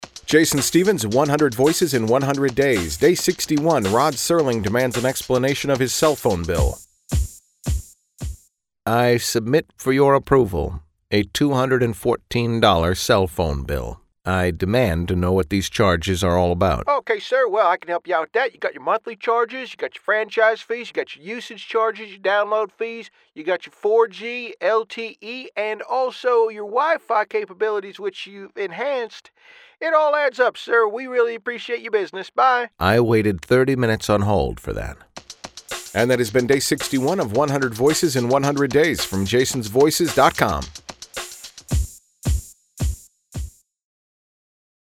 My Rod Serling impression is less an impression and more of an interpretation.
Tags: celebrity impersonations, Rod Serling impression, voice matching